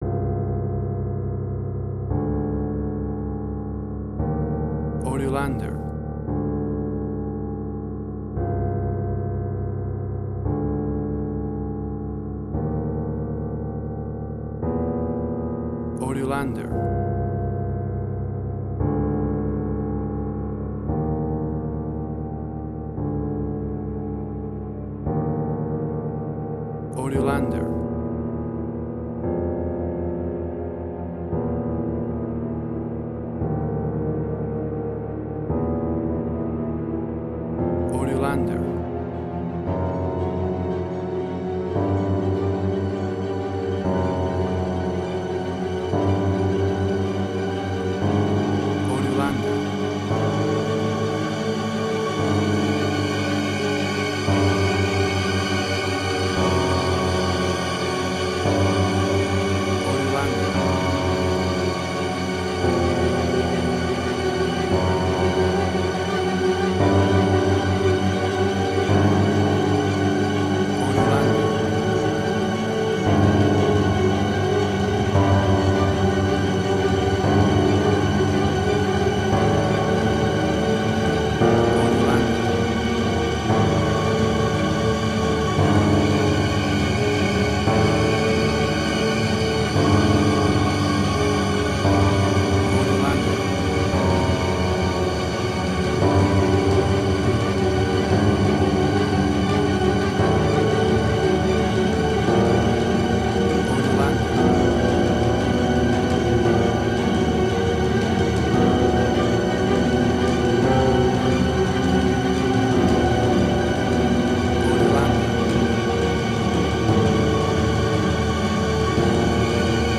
Dissonance Similar The Hurt Locker Fear
Tempo (BPM): 57